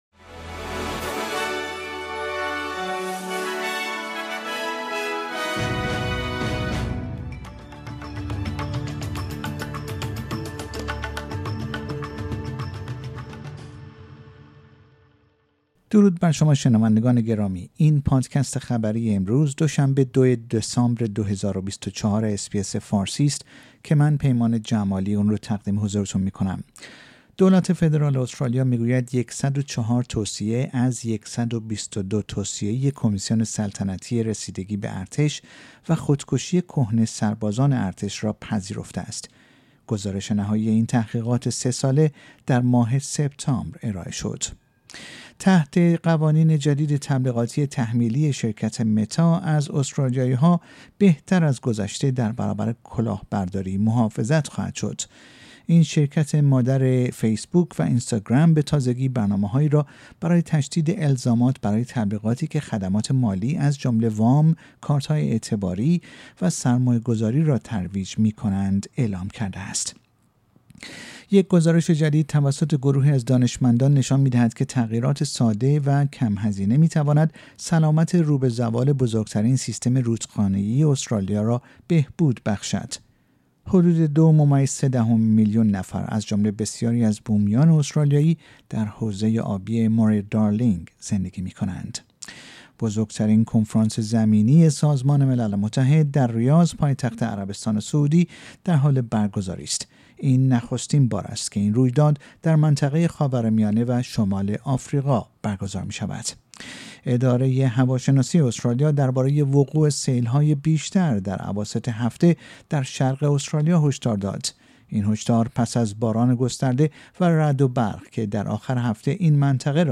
در این پادکست خبری مهمترین اخبار استرالیا در روز دو شنبه ۲ دسامبر ۲۰۲۴ ارائه شده است.